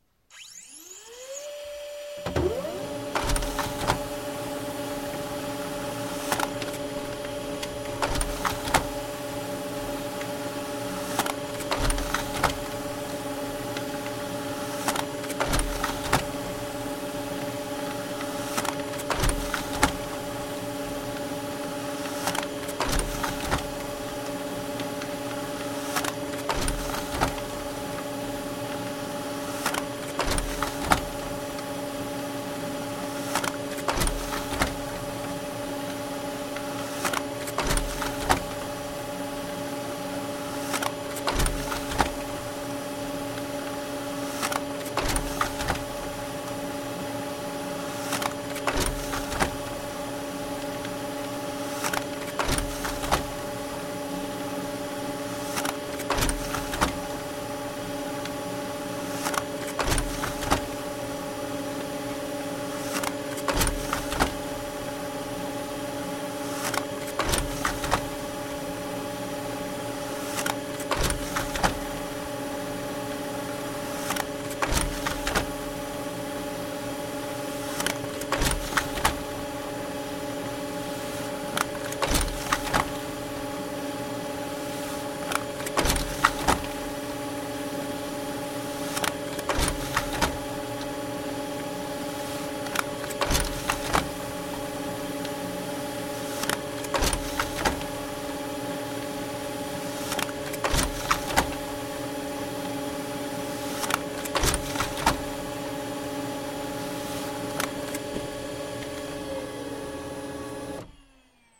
Звуки ксерокса
На этой странице собраны звуки ксерокса: от монотонного гула до ритмичного шума печати.
Звук полного цикла ксерокопирования нескольких копий документа